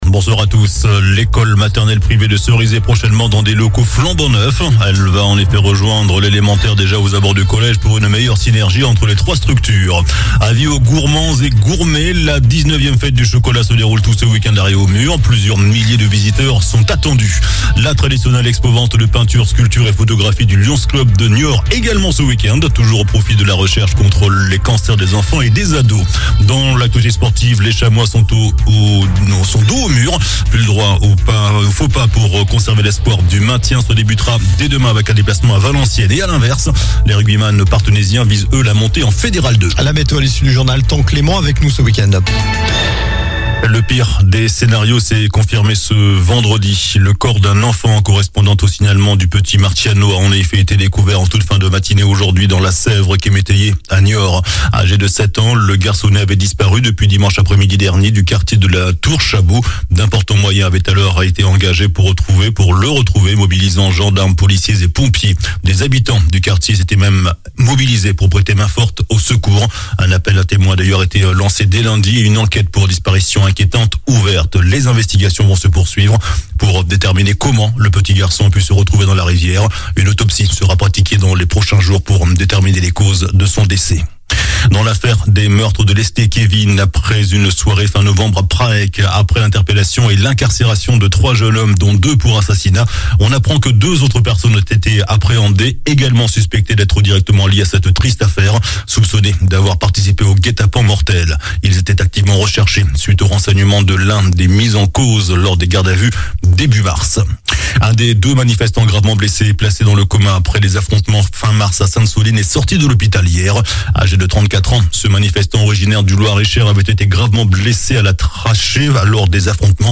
JOURNAL DU VENDREDI 14 AVRIL ( SOIR )